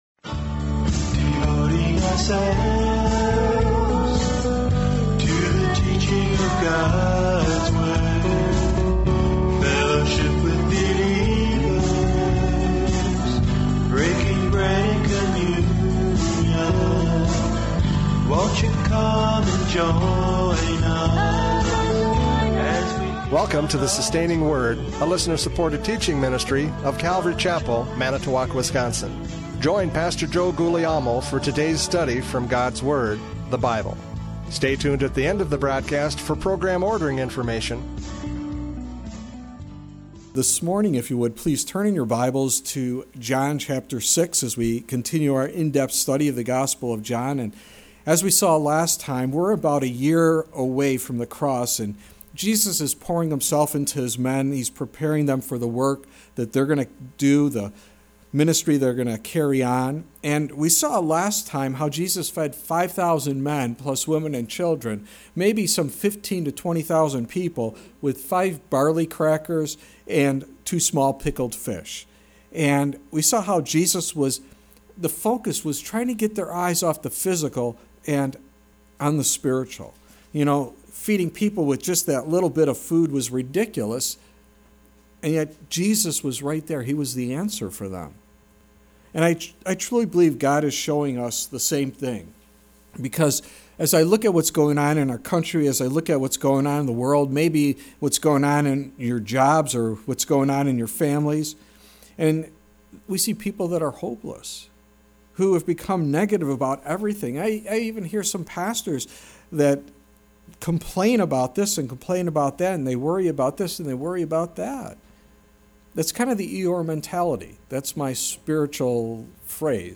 John 6:15-21 Service Type: Radio Programs « John 6:1-14 Spiritual Hunger!